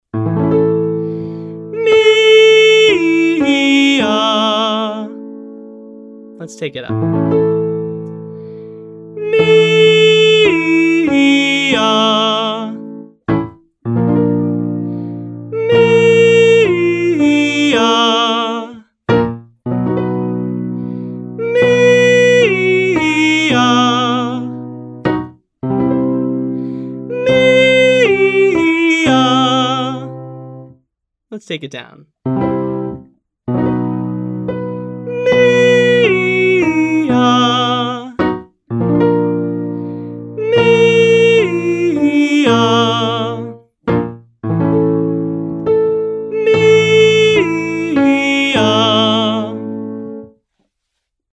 Belting
We’ll extend a bit higher now, into what some might call a twangy or head-dominant belt.